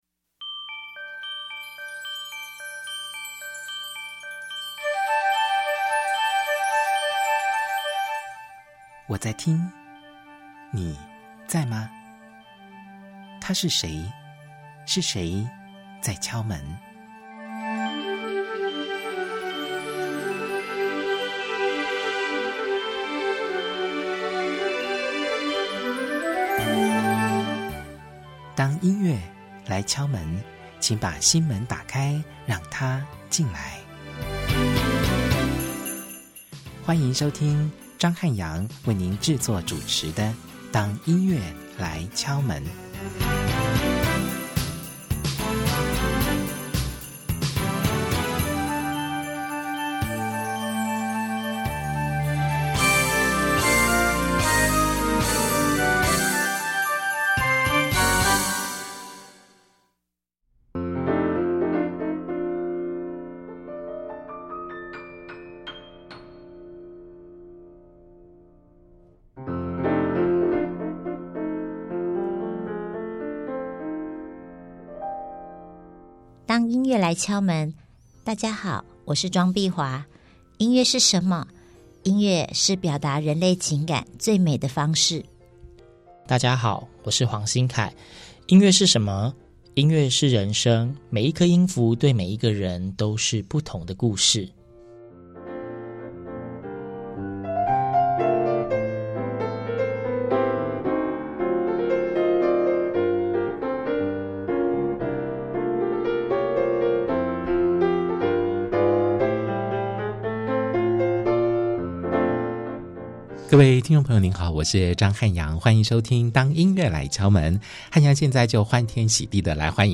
一連串的問題，都將在我們三個人的爆笑聲中得到解答。